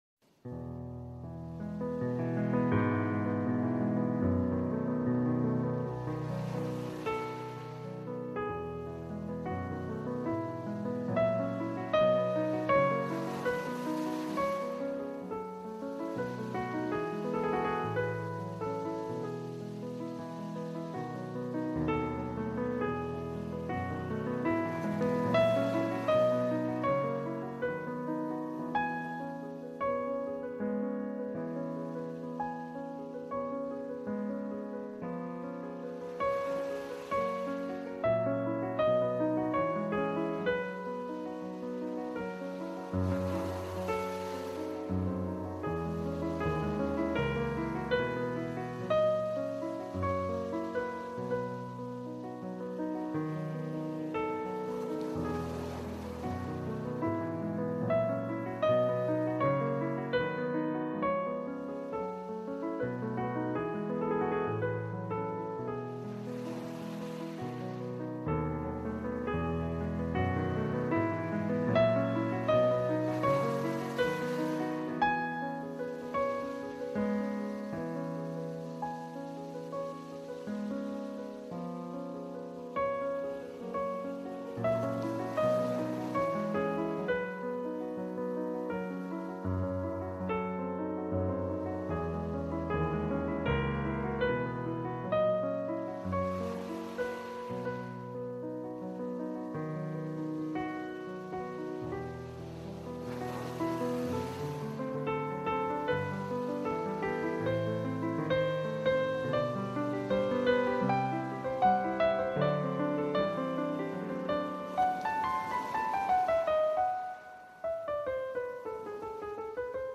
Victorienne Antique : Pluie Douce